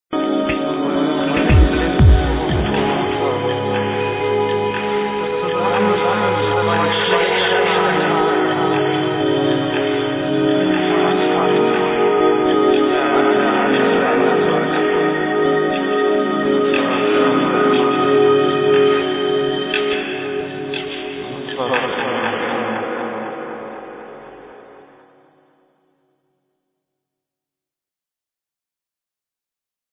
muzyka elektroniczna, ambient
electronic music